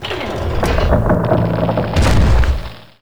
zep_trebuchet.wav